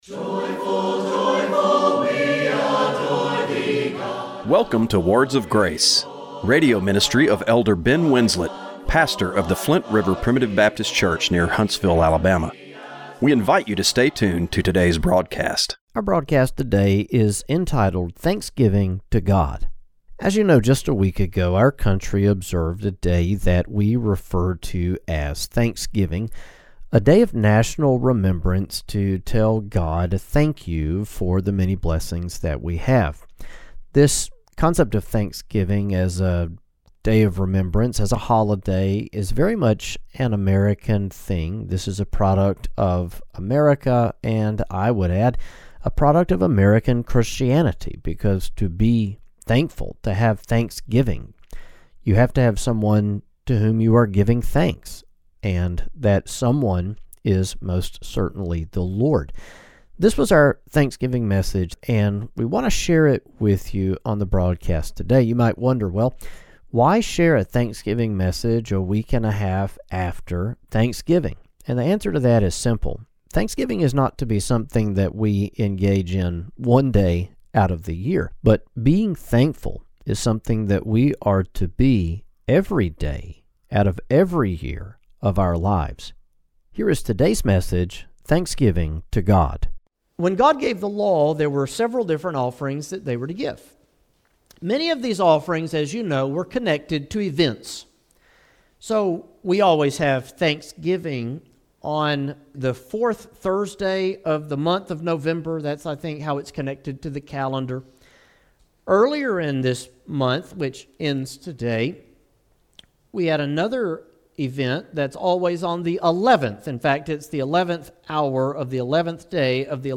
Radio broadcast for December 7, 2025.